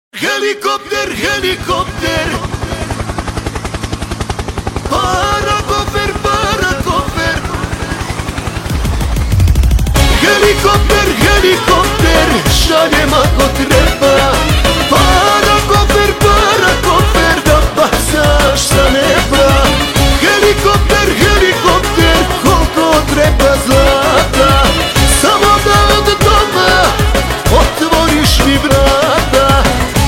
мужской голос
восточные мотивы
атмосферные
Интересная восточная композиция